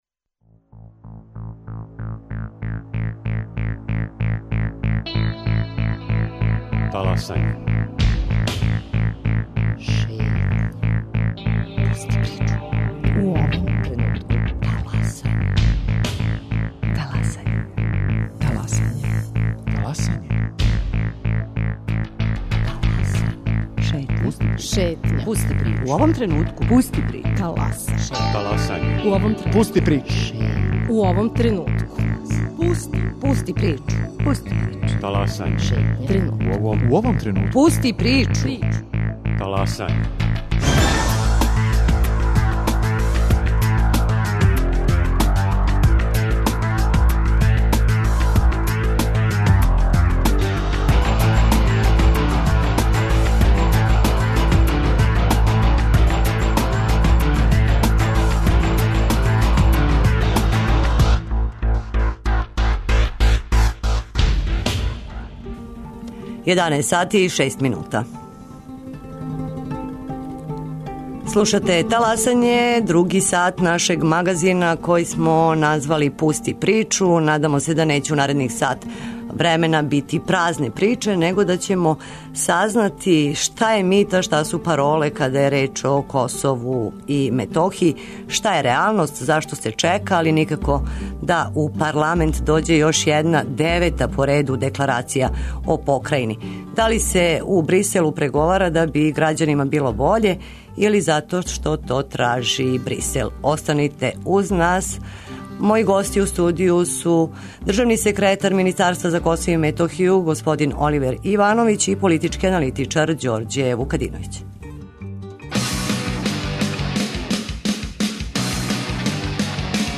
О декларацији, административним прелазима, државној политици према Косову и Метохији говоре државни секретар Министарства за Косово и Метохију Оливер Ивановић, политички аналитичар Ђорђе Вукадиновић и посланици Скупштине Србије.